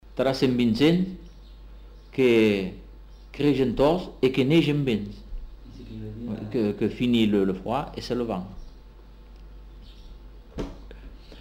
Lieu : Bagnères-de-Luchon
Genre : forme brève
Effectif : 1
Type de voix : voix d'homme
Production du son : récité
Classification : proverbe-dicton